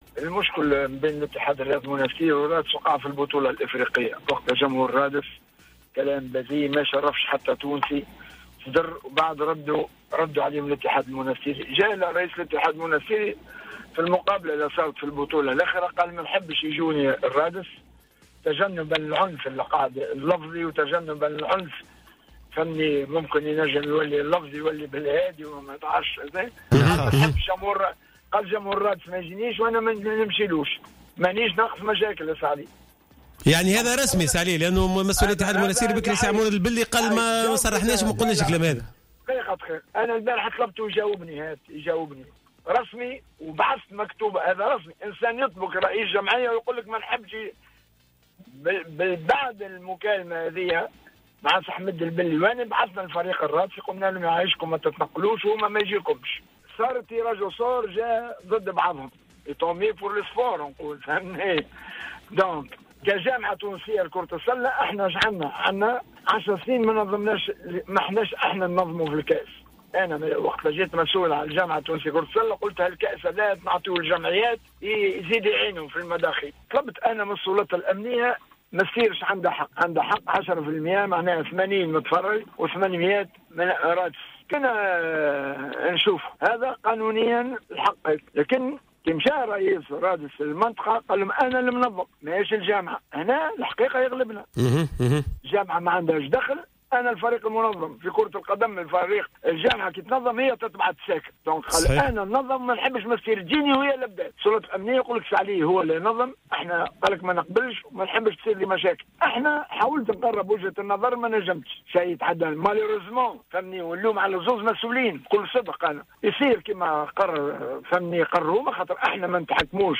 في مداخلة في برنامج 'راديو سبور'